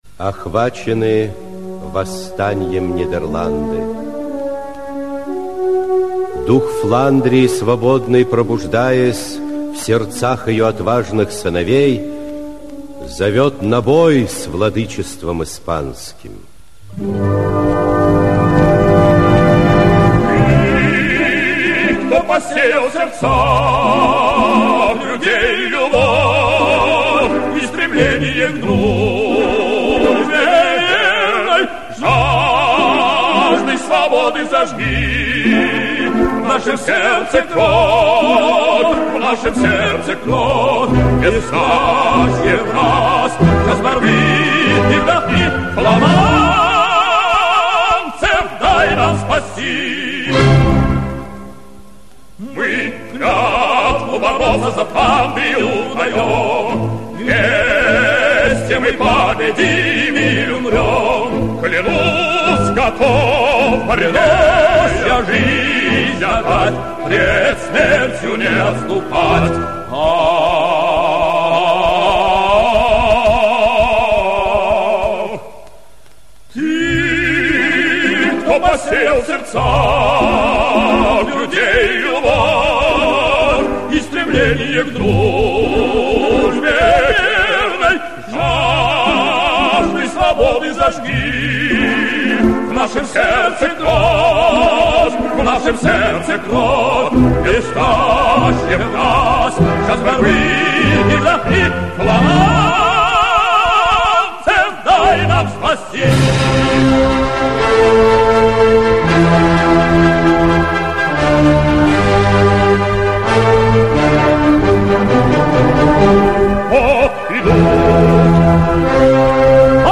Аудиокнига Дон Карлос (спектакль) | Библиотека аудиокниг
Aудиокнига Дон Карлос (спектакль) Автор Фридрих Шиллер Читает аудиокнигу Актерский коллектив.